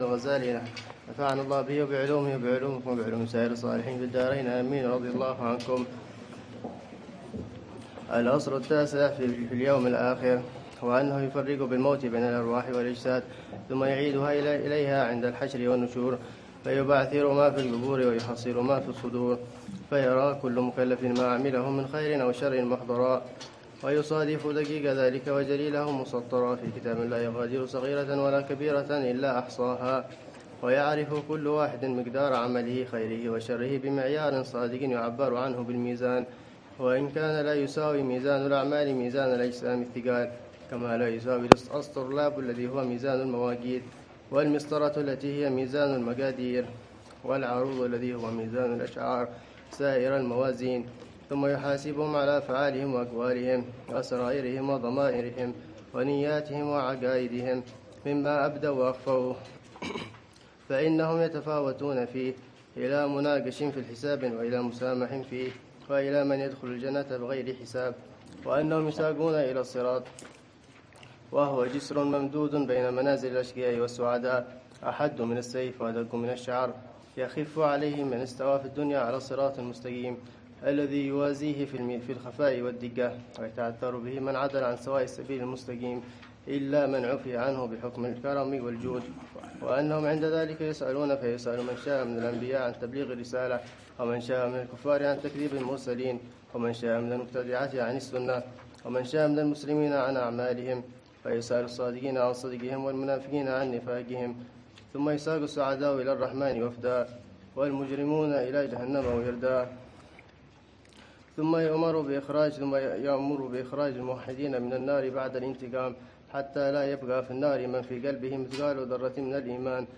الدرس الثالث للعلامة الحبيب عمر بن محمد بن حفيظ في شرح كتاب: الأربعين في أصول الدين، للإمام الغزالي .